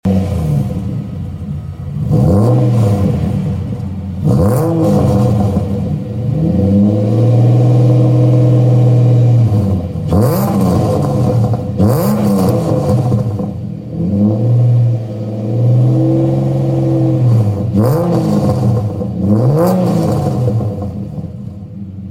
XR6 fitted with Redback Catback exhaust and tips, with rear muffler delete